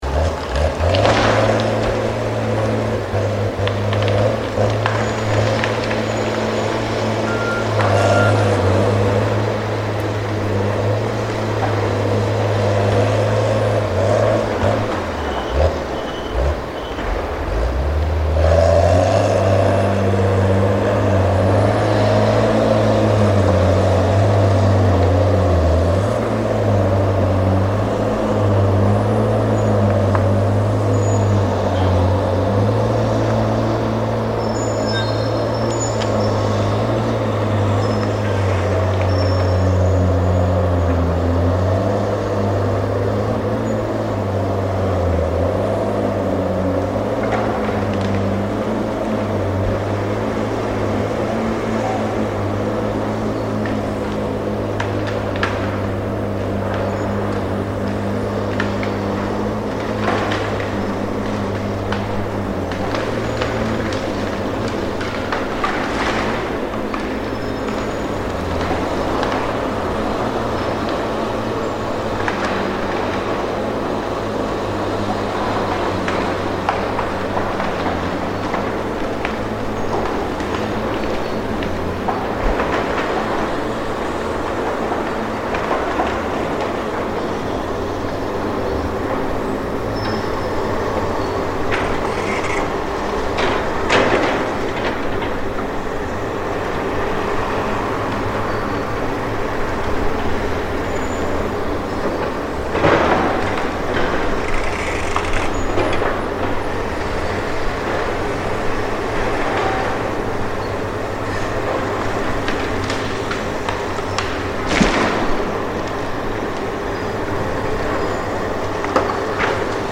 I found a spot to sit and listen to the sounds of destruction in what was once a place of serenity. This recording captures the sounds of machinery reducing a once serene forest to slash - a term used to describe the material left behind after trees are harvested. Not only has the forest been lost to the local community but it is has been lost to the local wildlife.
Recorded in Foxton Beach, New Zealand